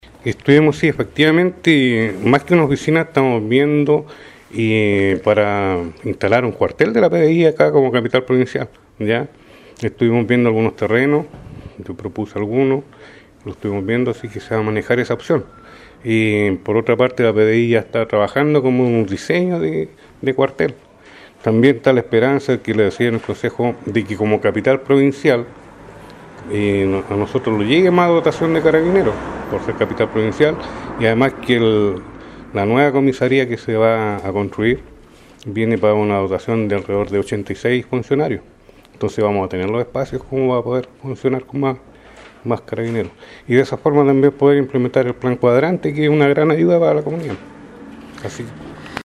Es por ello que el municipio de Quirihue pretende que se instale en la ciudad un cuartel de la PDI, según lo confirmó a Orbitanoticias el alcalde Richard Irribarra.
Alcalde-QUirihue-Cuartel-PDI.mp3